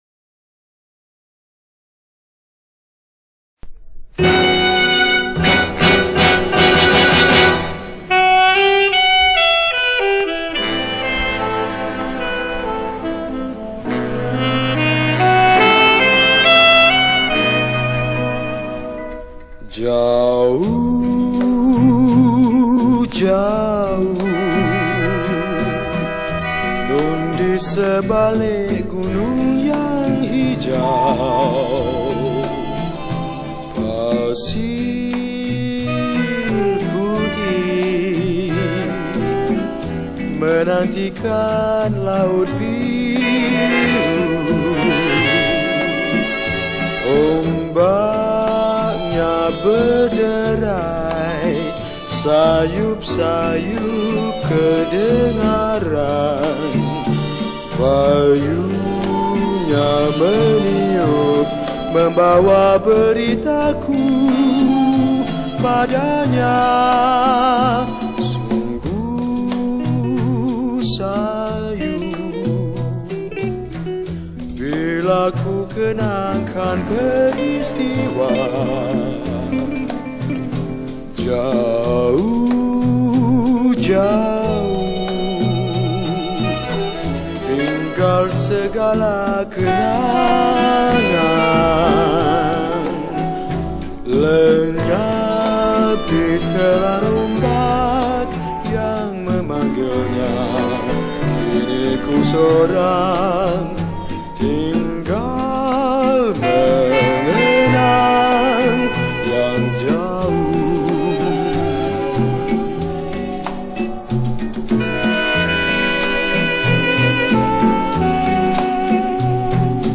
Malay Patriotic Song
Skor Angklung